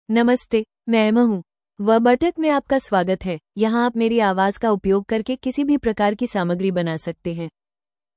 FemaleHindi (India)
EmmaFemale Hindi AI voice
Emma is a female AI voice for Hindi (India).
Voice sample
Listen to Emma's female Hindi voice.
Emma delivers clear pronunciation with authentic India Hindi intonation, making your content sound professionally produced.